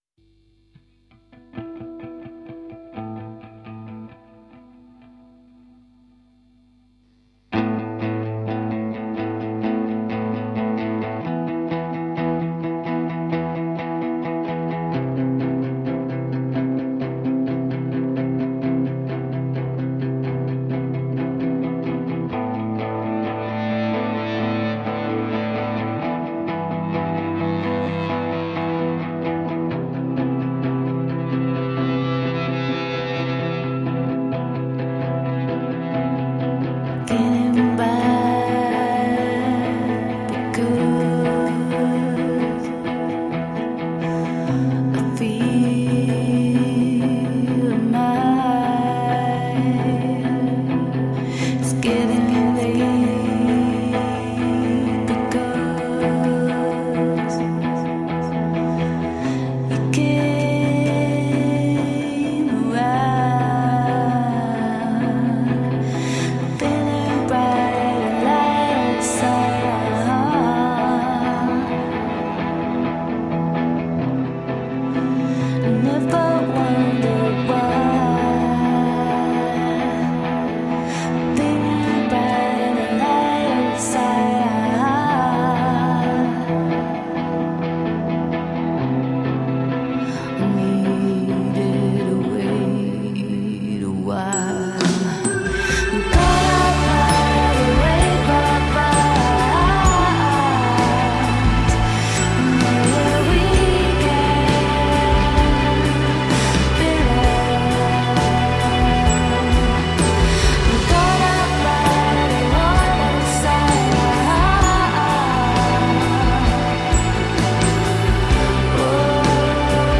Groovy, french downtempo electro-rock songs.
Tagged as: Electro Rock, Other, Woman Singing Electro Pop